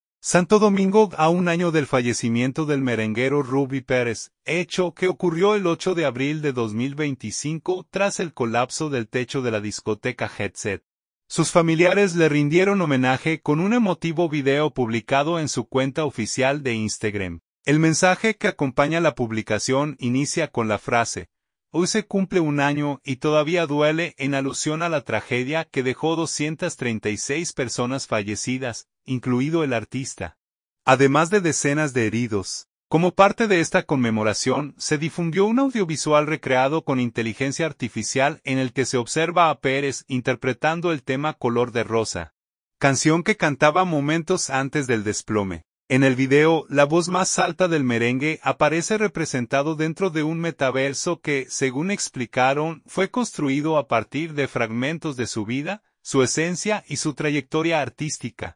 Santo Domingo.– A un año del fallecimiento del merenguero Rubby Pérez, hecho que ocurrió el 8 de abril de 2025 tras el colapso del techo de la discoteca Jet Set, sus familiares le rindieron homenaje con un emotivo video publicado en su cuenta oficial de Instagram.
Como parte de esta conmemoración, se difundió un audiovisual recreado con inteligencia artificial en el que se observa a Pérez interpretando el tema “Color de rosa”, canción que cantaba momentos antes del desplome.